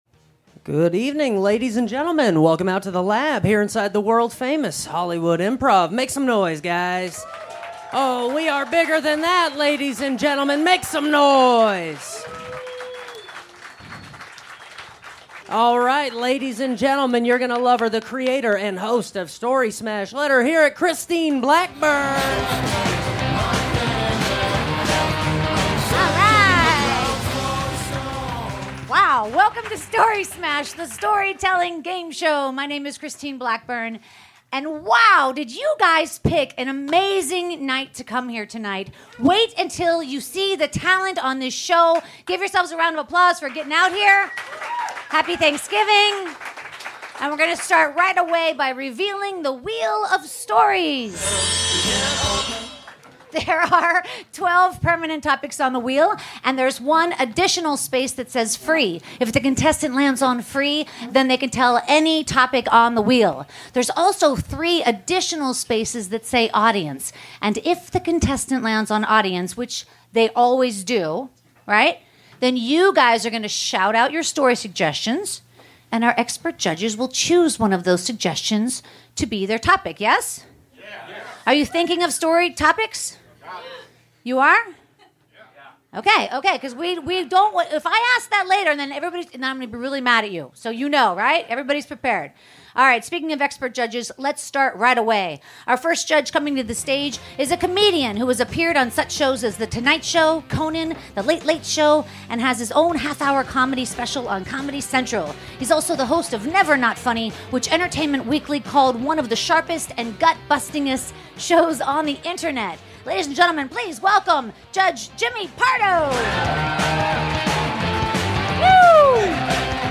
528 - Story Smash the Storytelling Gameshow LIVE at the Hollywood Improv!
Recorded November 24th, 2018 at The Hollywood Improv.